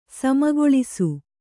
♪ samagoḷisu